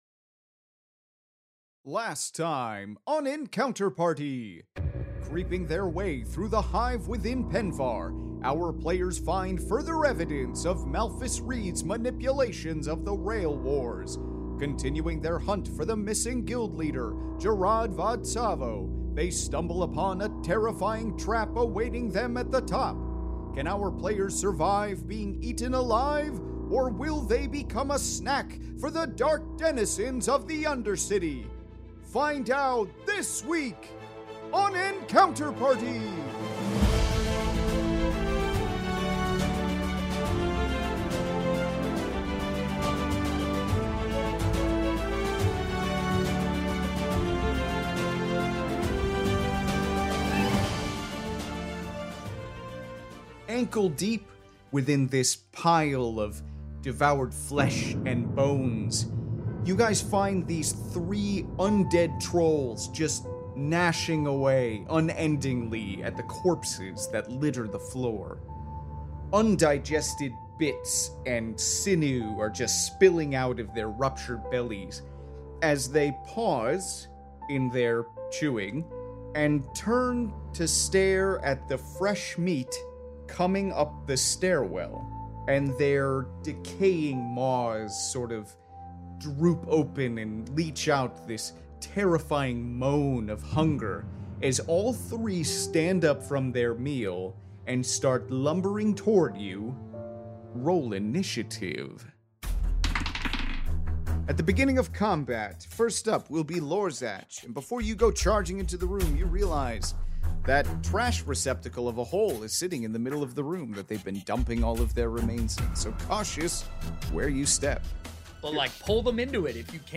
Fantasy Mystery Audio Adventure